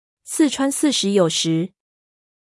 • ピンイン: sì chuān sì shí yǒu shí